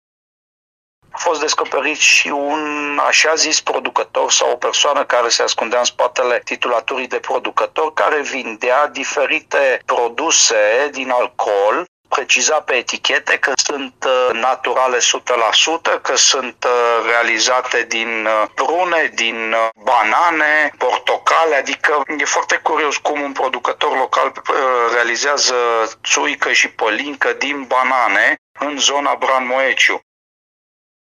Șeful Comisariatului Regional pentru Protecția Consumatorului Brașov, Sorin Susanu: